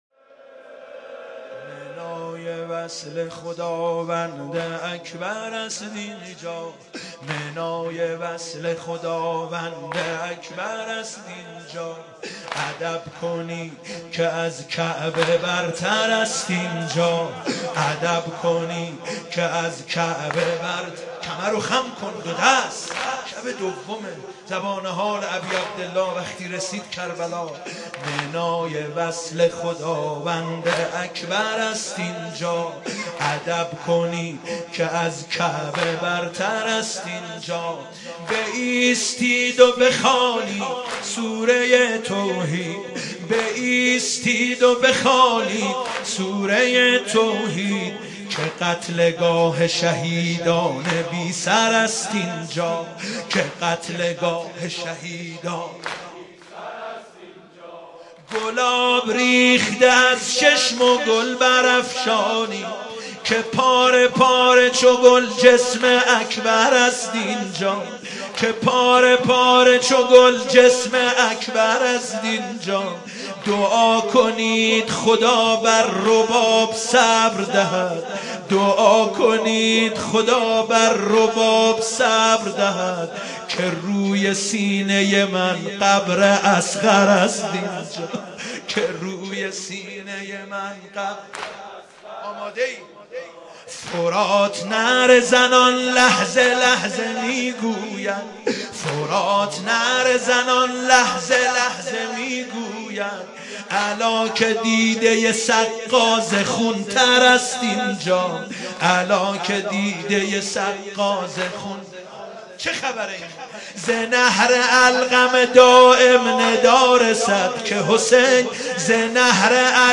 مداحی جدید
شب دوم محرم97 تهران مسجد امیر